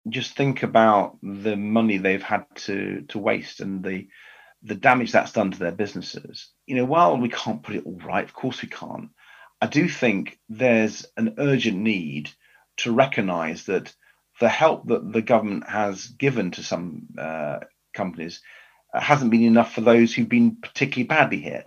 MP for Kingston Ed Davey speaks about the impact on small breweries